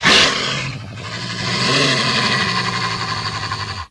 pdog_aggression_1.ogg